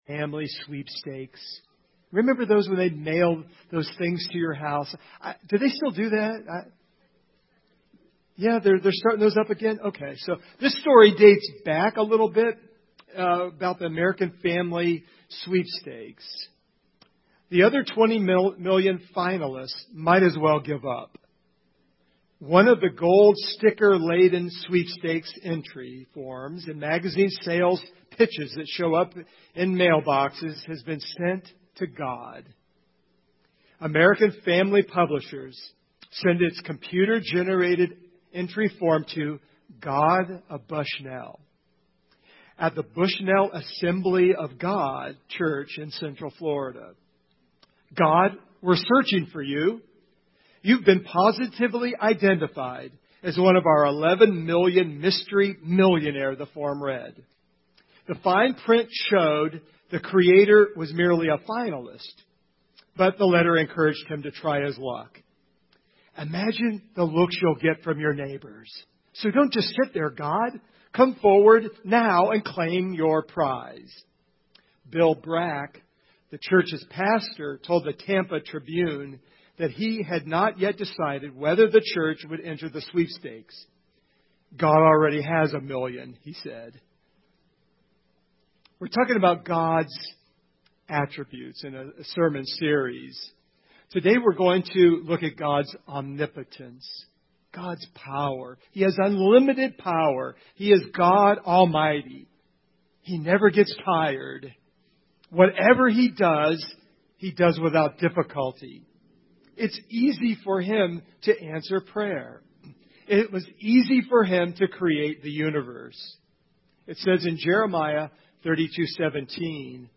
Jeremiah 32:17 Service Type: Sunday Morning God has omnipotent power.